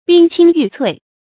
冰清玉粹 bīng qīng yù cuì 成语解释 比喻德行高洁。